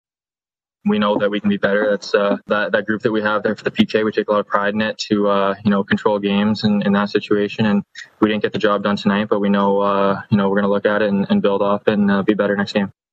New third-line center Mark Jankowski scored the first goal of the 2021 NHL season.  He says the Penguins will get better.